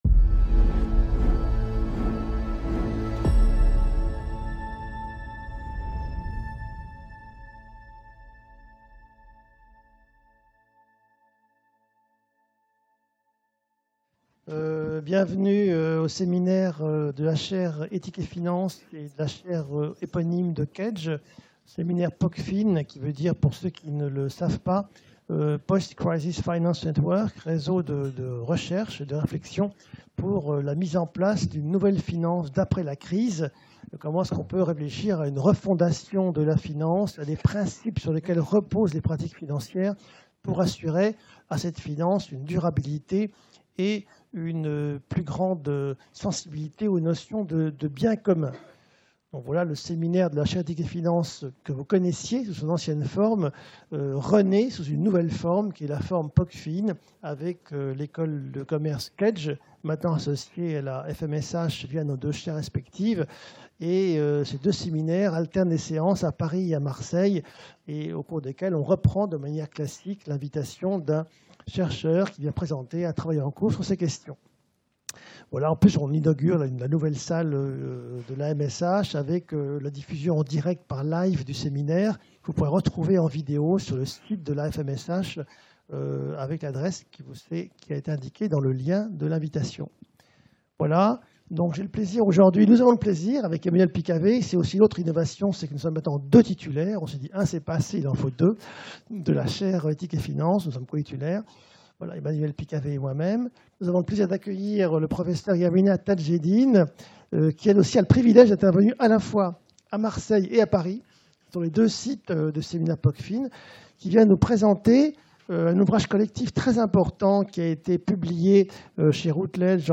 Séminaire PoCfin